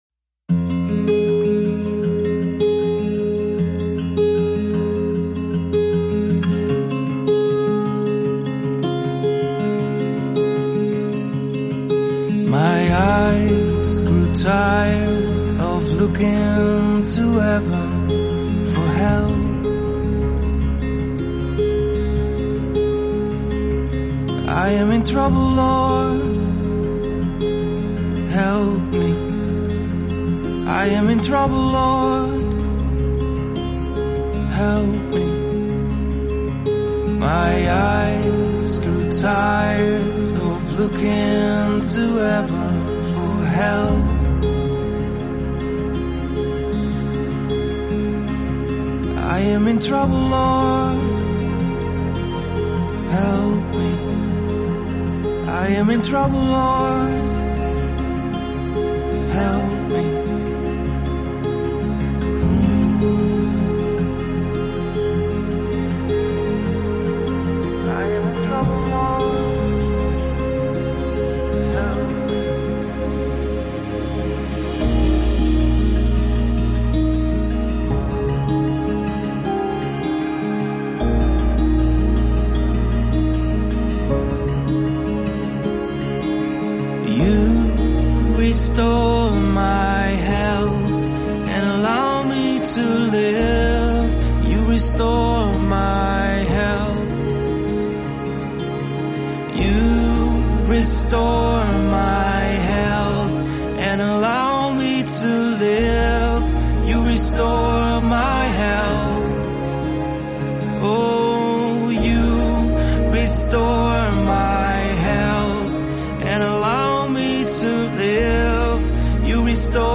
Hear the Words of the Father sung to and over you.